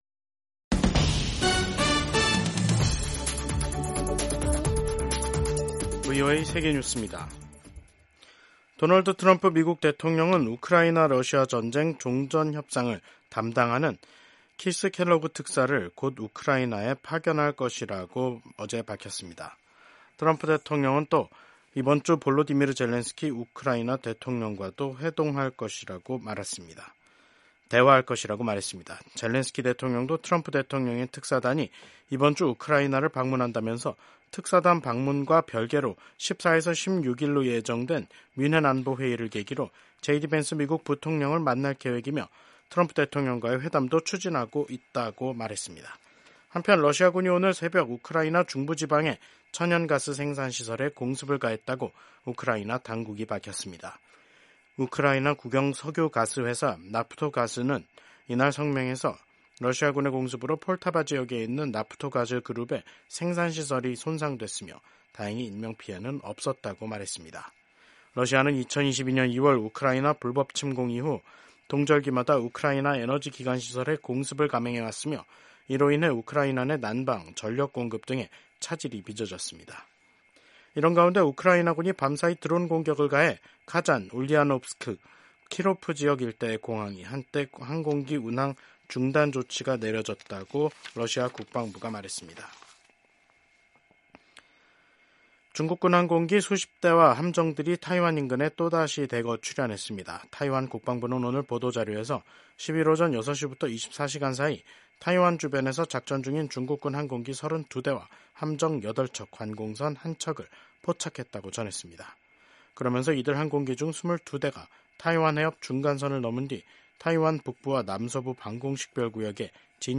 세계 뉴스와 함께 미국의 모든 것을 소개하는 '생방송 여기는 워싱턴입니다', 2025년 2월 11일 저녁 방송입니다. 유럽연합(EU)이 도널드 트럼프 미국 대통령의 관세 예고에 강력히 대응하겠다고 선언했습니다. 도널드 트럼프 미국 대통령이 15일 정오까지 팔레스타인 무장 정파 하마스가 이스라엘 인질 전원을 석방하지 않으면 지옥과 같은 상황이 전개될 것이라고 경고했습니다.